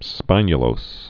(spīnyə-lōs)